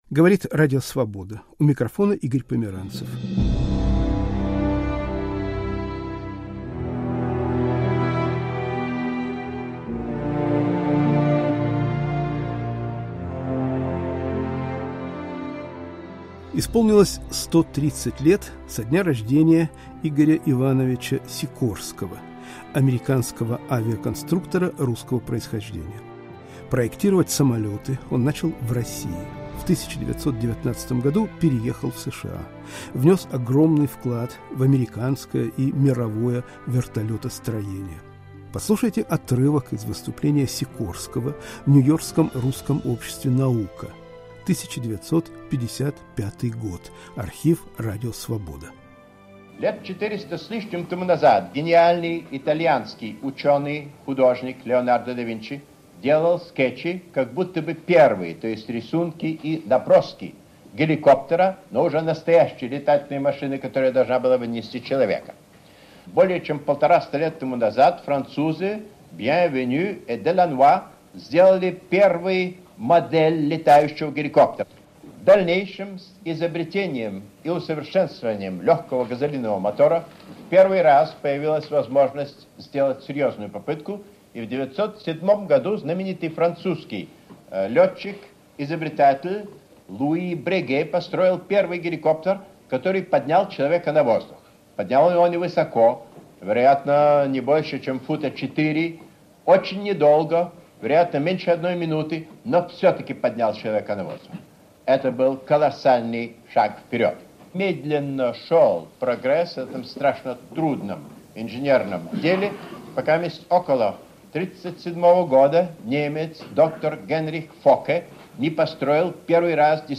Голос Сикорского из архива радио «Свобода» (1955 г.).